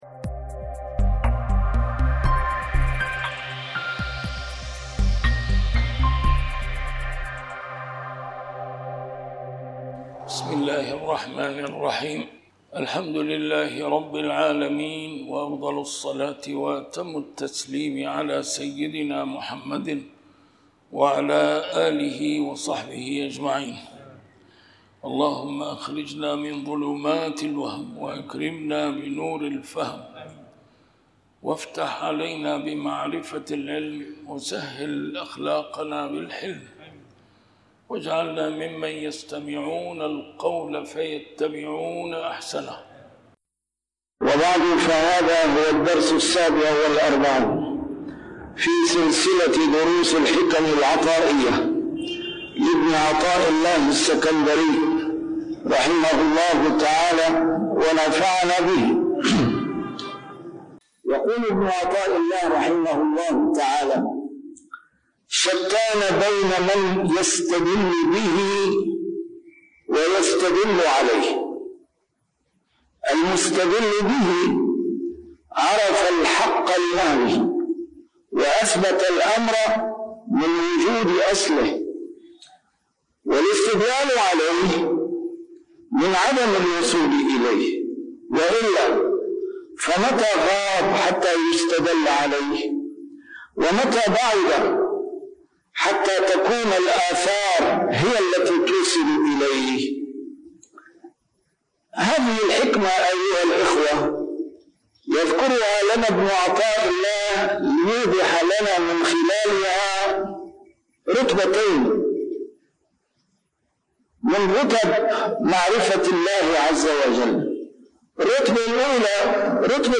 A MARTYR SCHOLAR: IMAM MUHAMMAD SAEED RAMADAN AL-BOUTI - الدروس العلمية - شرح الحكم العطائية - الدرس رقم 47 شرح الحكمة 29